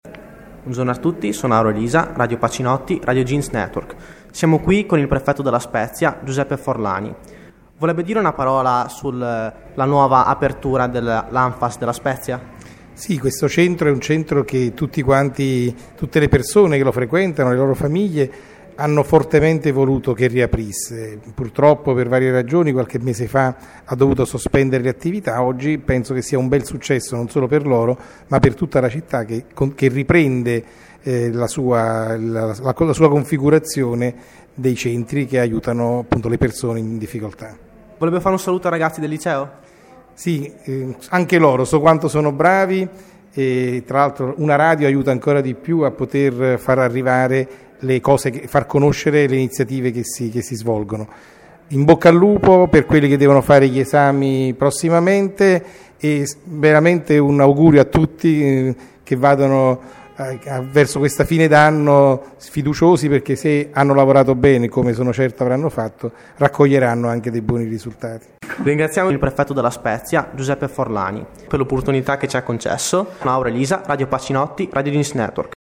Intervista a Giuseppe Forlani
play_circle_filled Intervista a Giuseppe Forlani Radioweb Pacinotti Prefetto della Spezia intervista del 13/06/2012 Si è svolta nei locali dell'ANFFAS la cerimonia di riapertura dopo le traversie economiche che avevano costretto ad eliminare il supporto ai disabili, qui abbiamo incontrato dirigenti e personalità politiche e religiose.